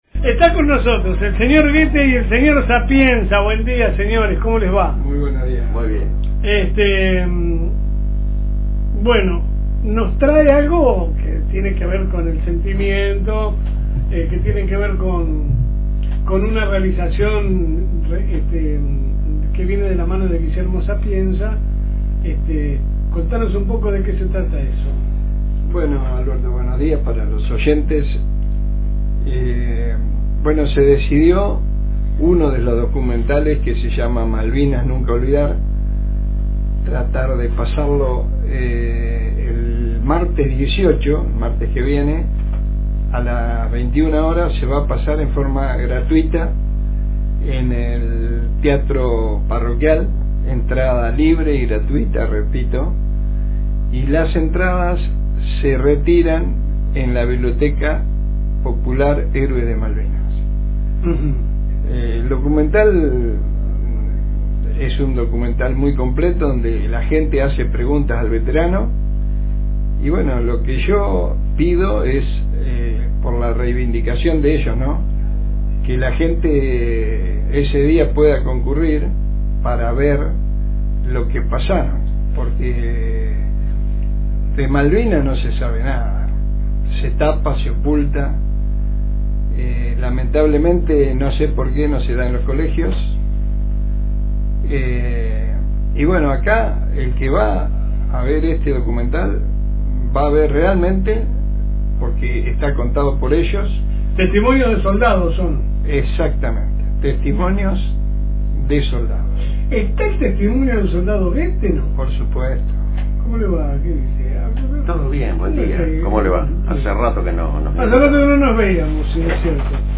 AUDIO – Nuevo documental